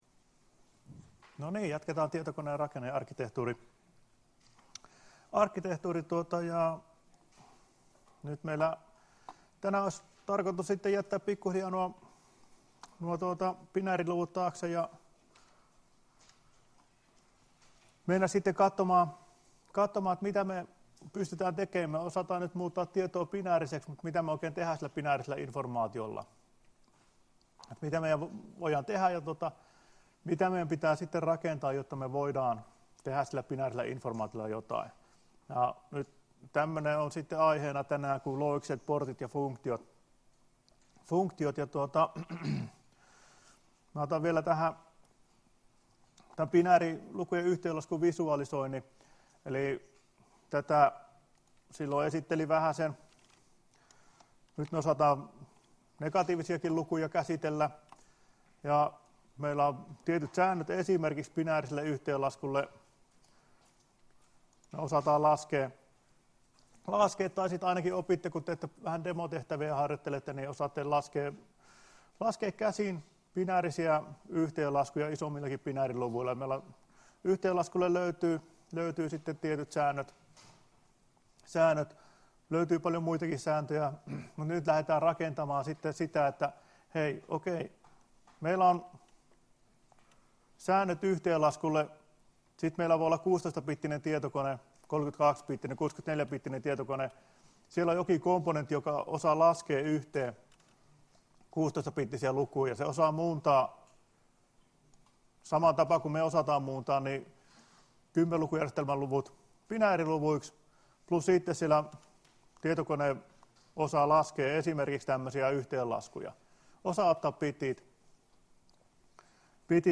Luento 6.11.2017 — Moniviestin